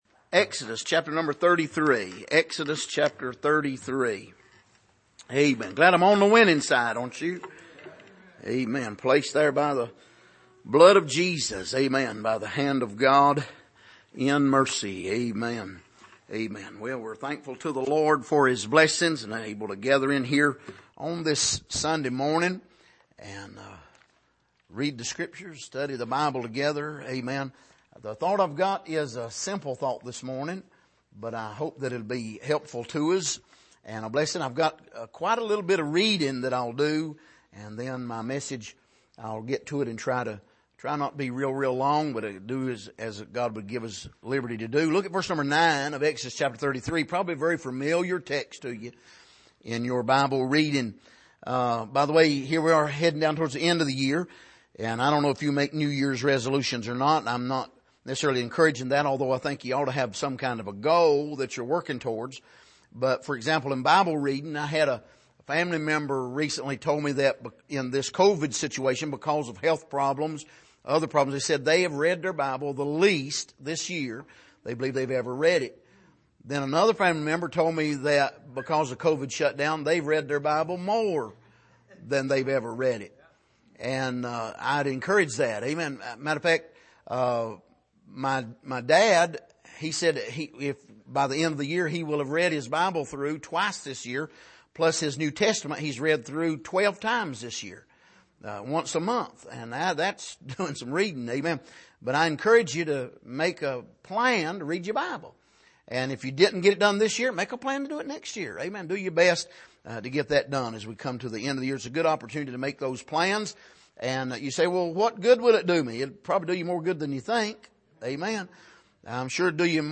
Passage: Exodus 33:9-23 Service: Sunday Morning